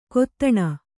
♪ kottaṇa